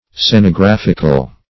Search Result for " scenographical" : The Collaborative International Dictionary of English v.0.48: Scenographic \Scen`o*graph"ic\, Scenographical \Scen`o*graph"ic*al\, a. [Cf. F. sc['e]nographique, Gr.